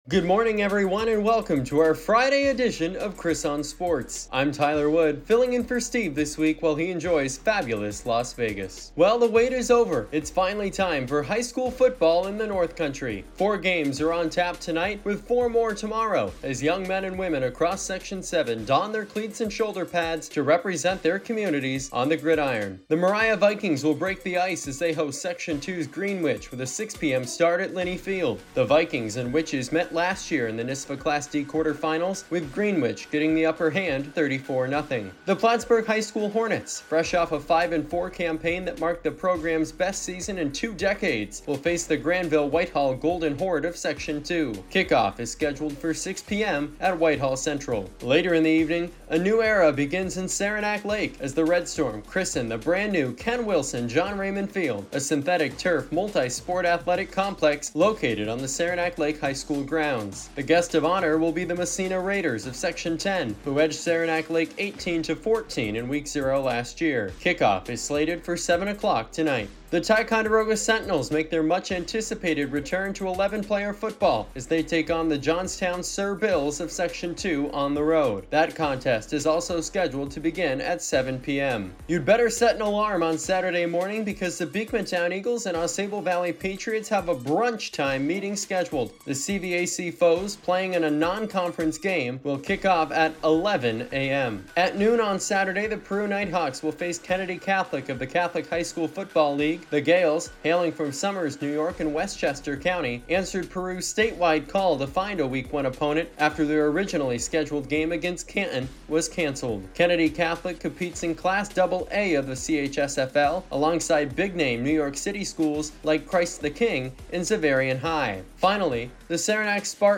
LIVE PLAY-BY-PLAY